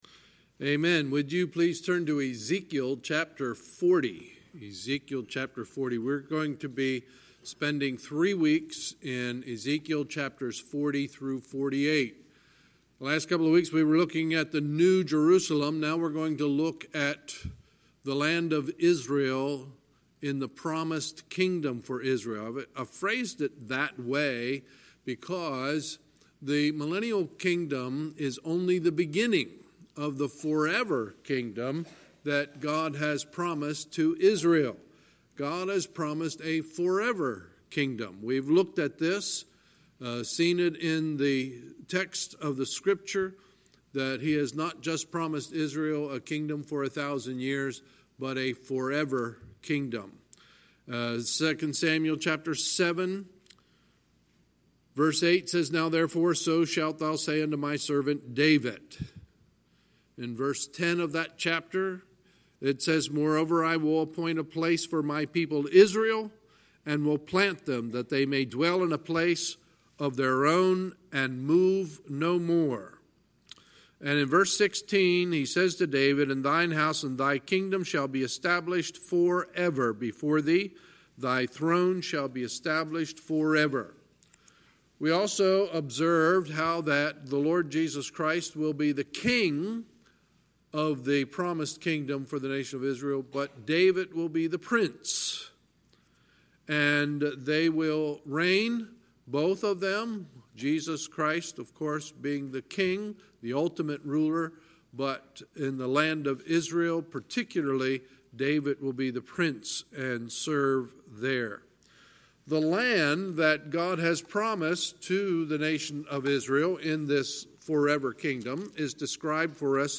Sunday, September 1, 2019 – Sunday Evening Service